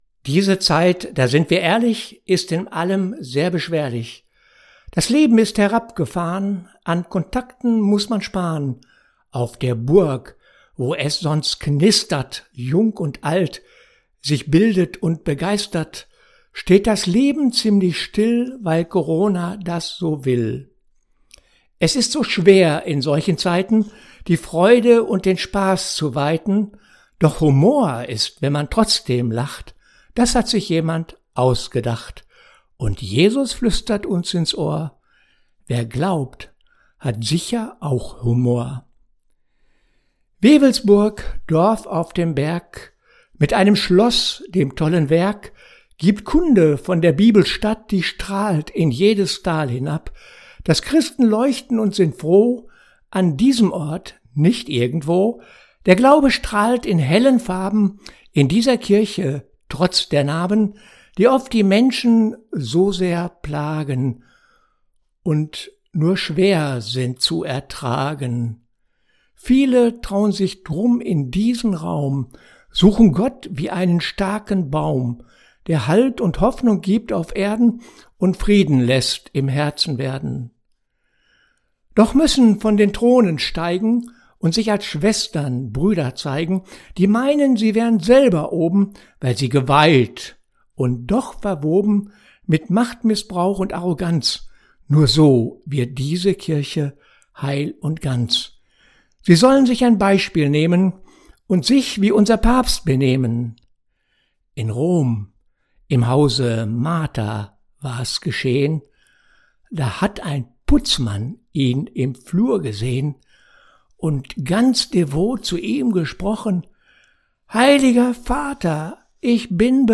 Büttenpredigt
Buettenpredigt.mp3